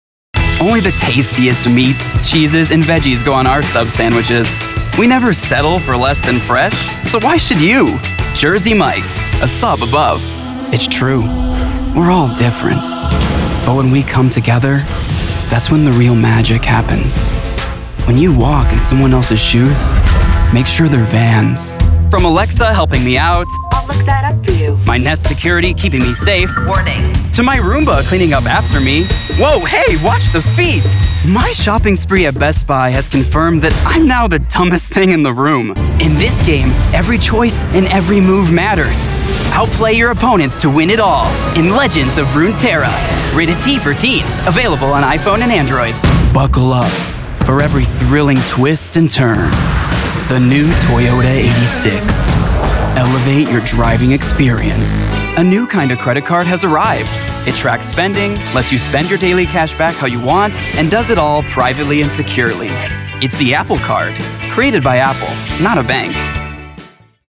COMMERCIAL 💸
conversational
quirky
sincere
warm/friendly